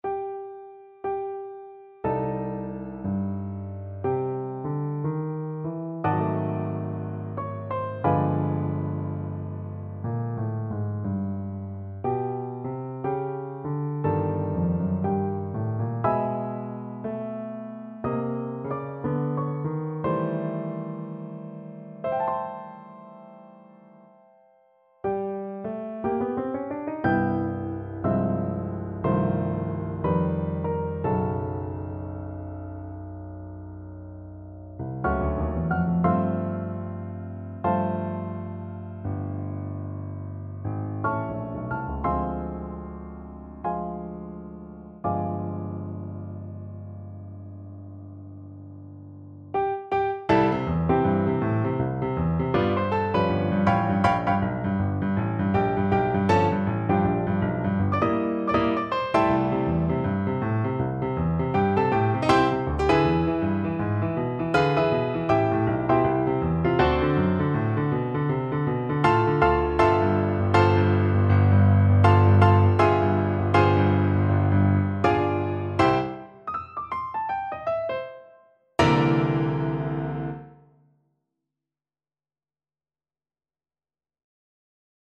Free Sheet music for Piano Four Hands (Piano Duet)
Freely, bluesy and smoochy =60
4/4 (View more 4/4 Music)
Jazz (View more Jazz Piano Duet Music)